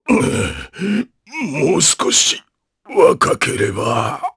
Ricardo-Vox_Dead_jp.wav